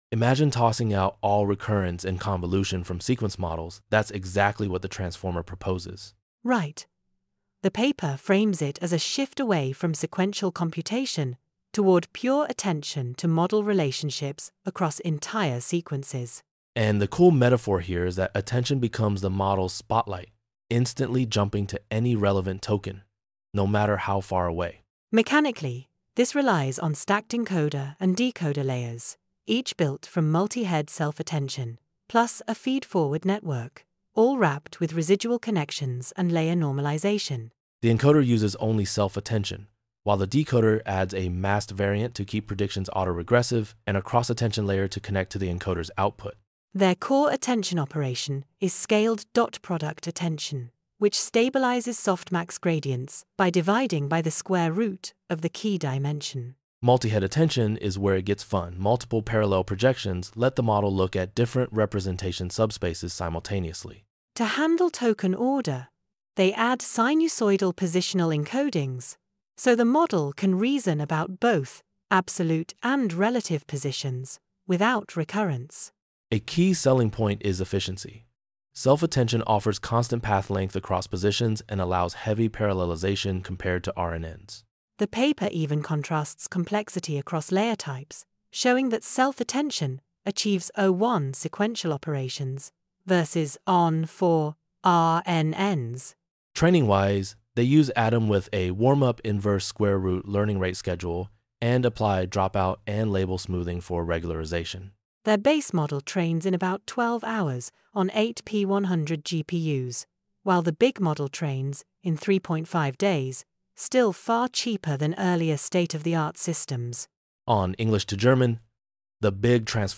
• Podcast: generate an audio narration you can listen to anytime